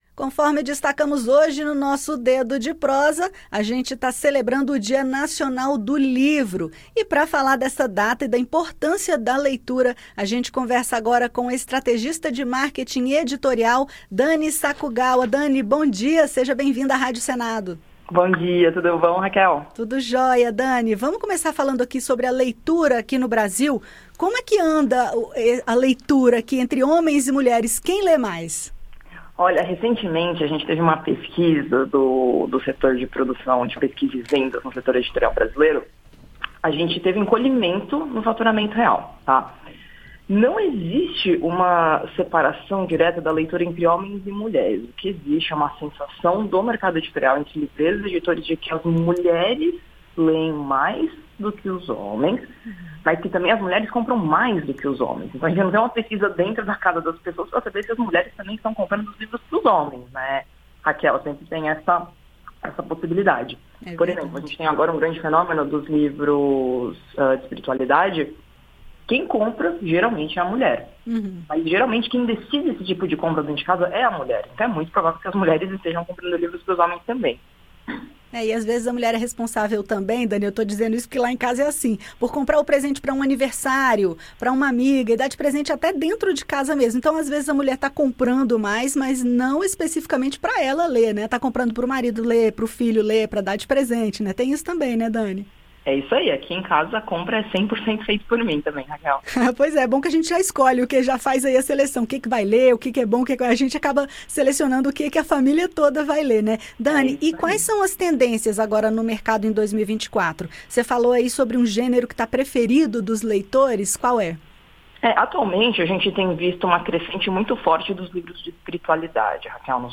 Ela destaca as tendências do mercado editorial em 2024, fala um pouco do gênero preferido dos leitores brasileiros e dá várias dicas para criar o hábito de leitura, tão importante para o enriquecimento cultural e de conhecimentos. Acompanhe a entrevista.